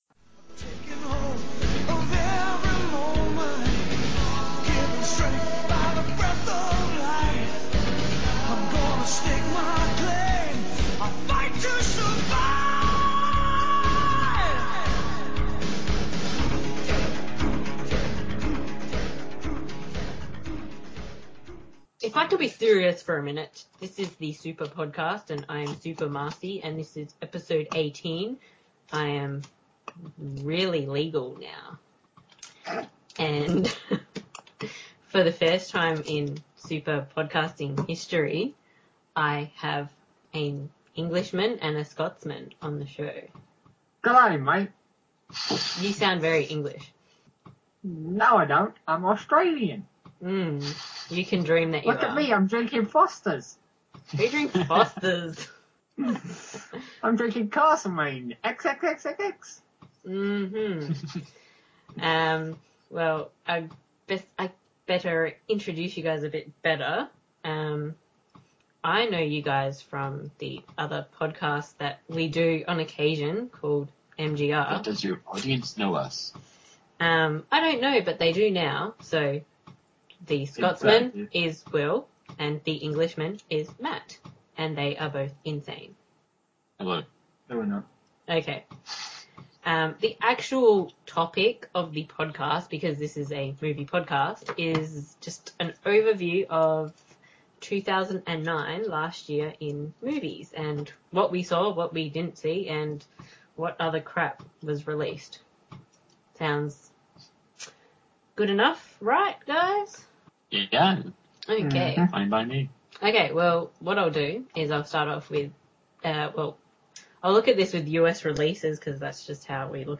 The first ever British and Scottish guests on Super Podcast, I am branching out! Being that it is a new year we thought we would go over the films of 2009, what we saw, what we liked, what we didn’t like and what we did not see.